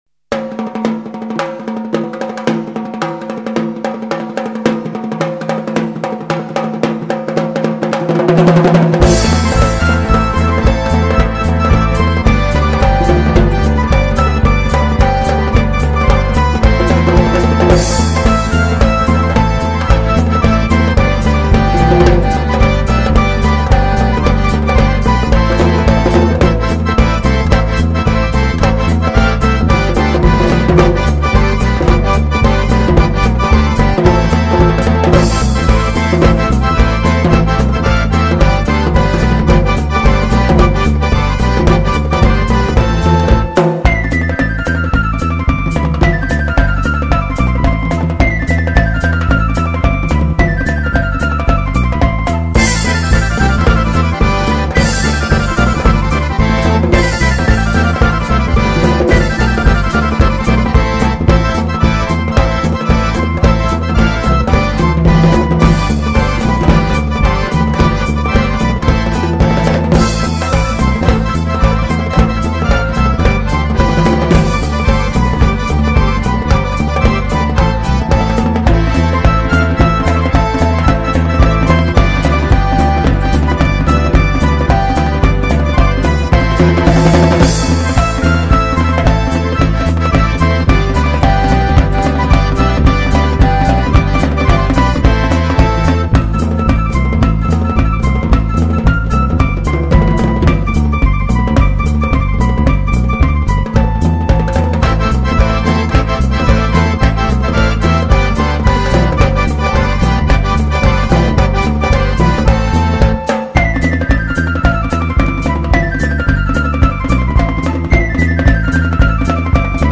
Грузинская_народная
Gruzinskaya_narodnaya.mp3